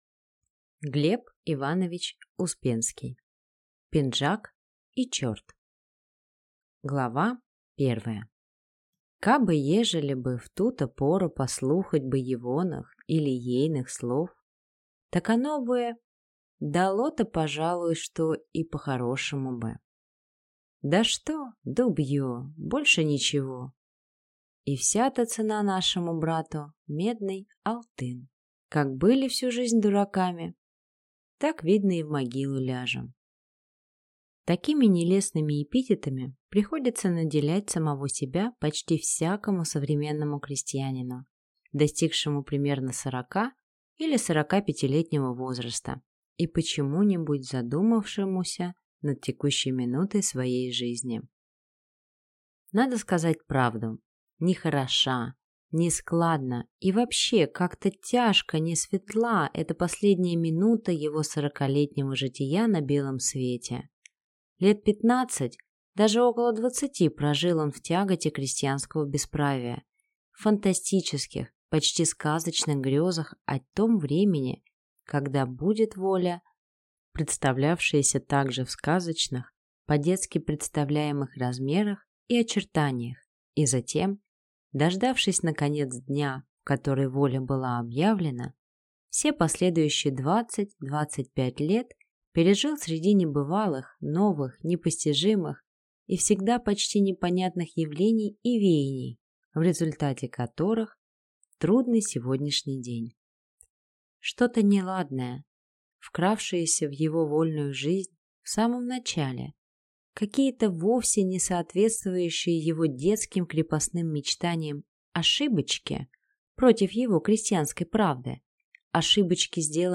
Аудиокнига «Пинжак» и чорт | Библиотека аудиокниг